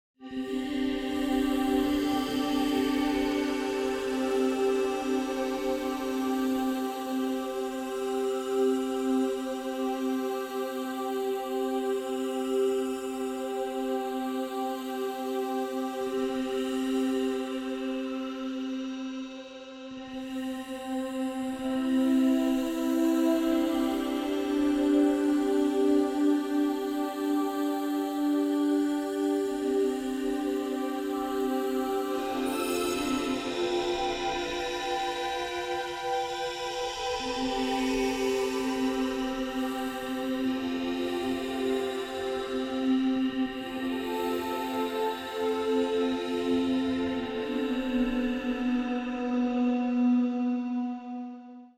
All such variations created different unique music.